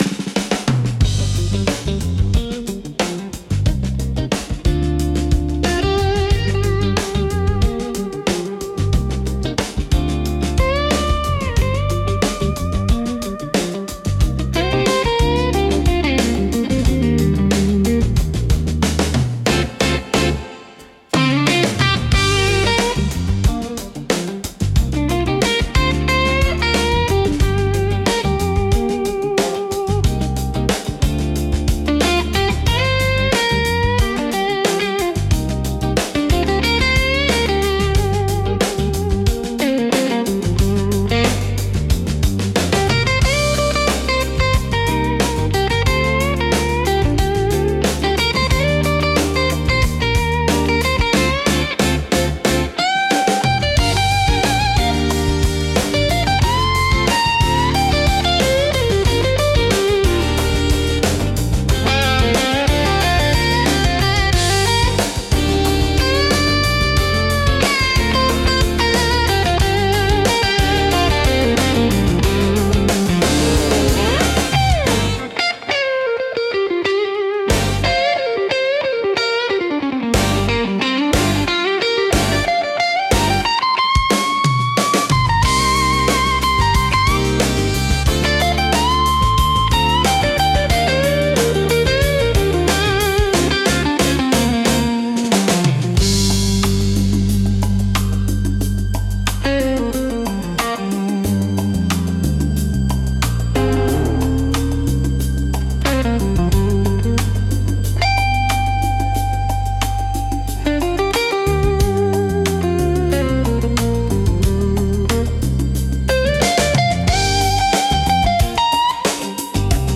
ギターやハーモニカによる哀愁のあるメロディとリズムが、深い感情表現を生み出します。